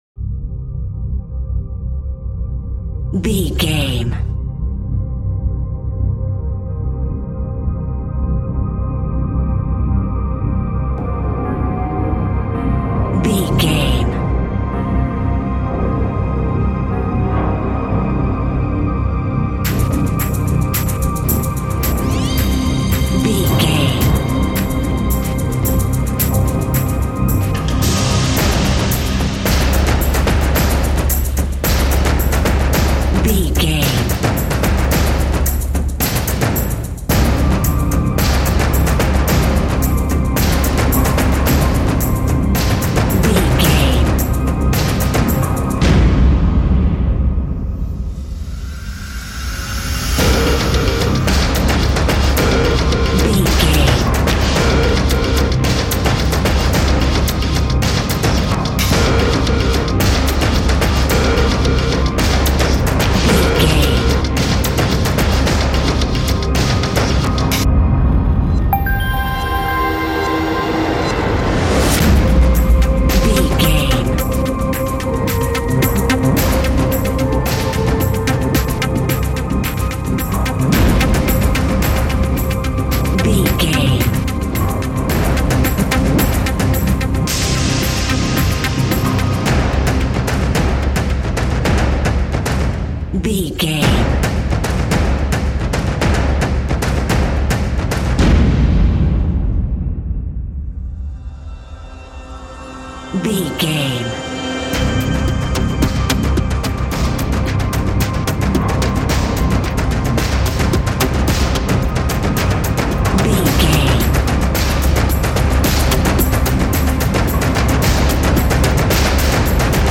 Fast paced
In-crescendo
Ionian/Major
dark ambient
EBM
drone
synths
Krautrock